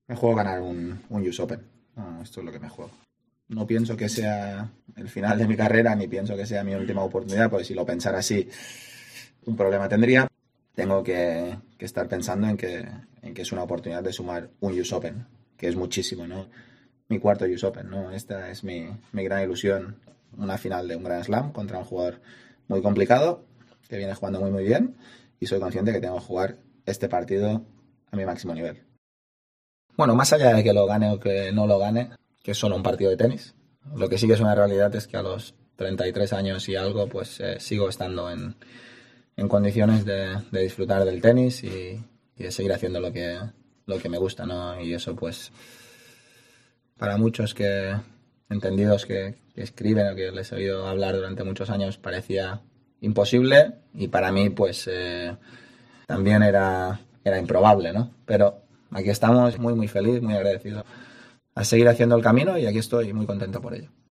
"El primer set fue un poco frustrante porque tuve muchos puntos de rotura antes del tie-break y él no tuvo ninguno. En el 'tie-break' tuve un poco de suerte porque tuvo 5/2 y 4/0, pero sobreviví en ese momento y finalmente obtuve el descanso en el segundo y luego el partido cambió por completo", resumió Nadal sobre la pista.